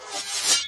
grasping_hands_end.ogg